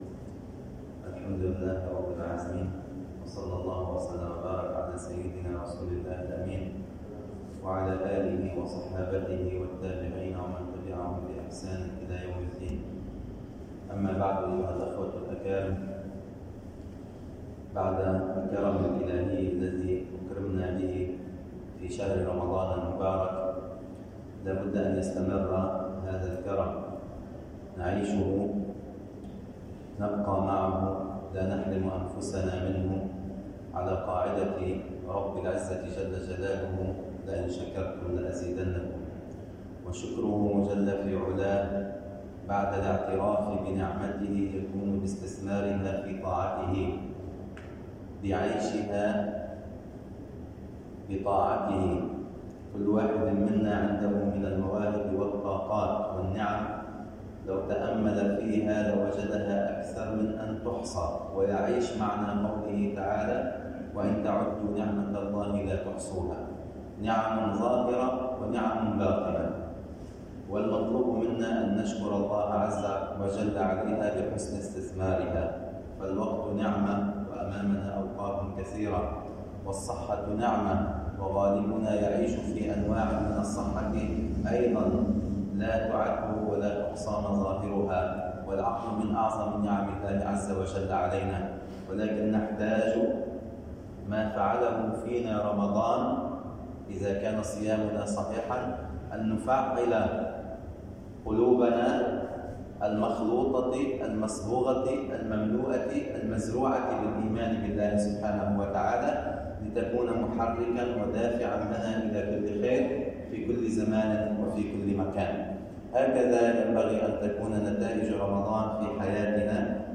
[منبر الجمعة]